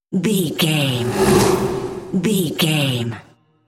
Whoosh airy creature
Sound Effects
Atonal
ominous
haunting
eerie